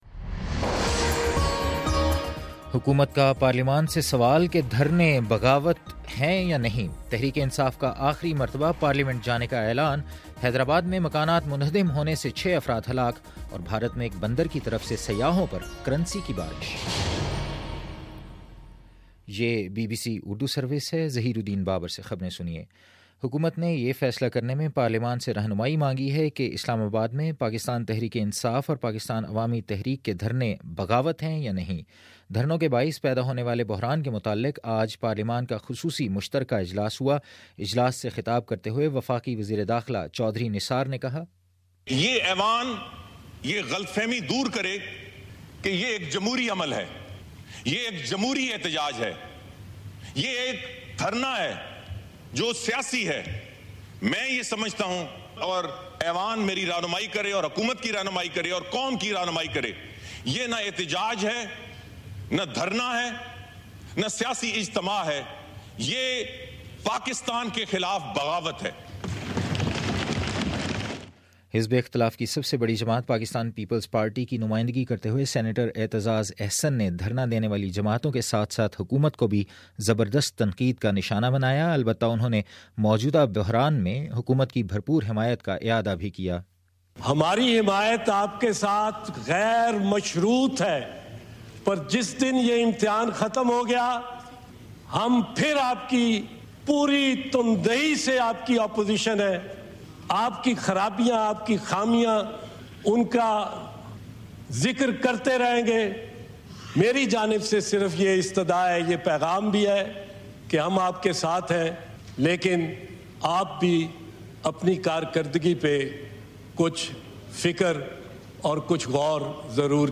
دو ستمبر : شام چھ بجے کا نیوز بُلیٹن
دس منٹ کا نیوز بُلیٹن روزانہ پاکستانی وقت کے مطابق صبح 9 بجے، شام 6 بجے اور پھر 7 بجے۔